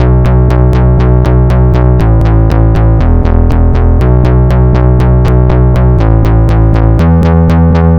Sounds Moroder B 120.wav